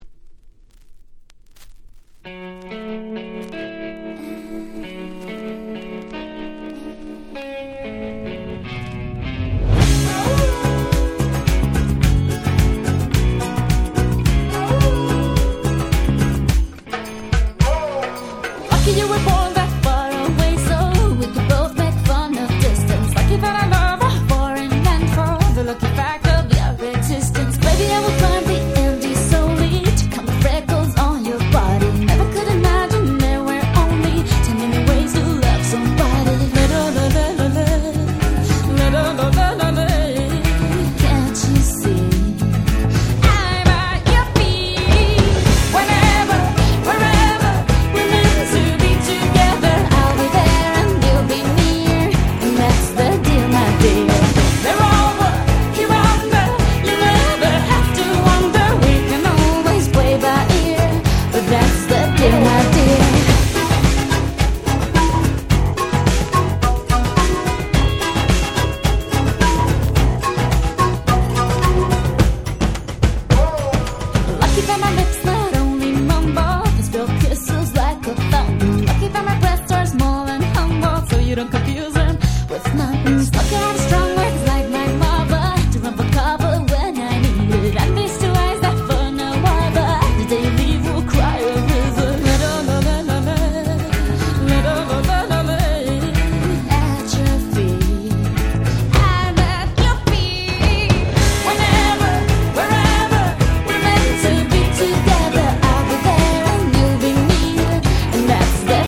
問答無用の最強ラテンダンスナンバー！！
キャッチー系